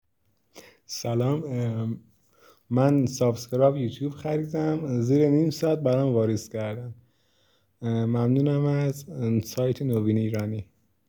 نظرات مثبت مشتریان عزیزمون با صدا خودشون رضایت از سایت نوین ایرانی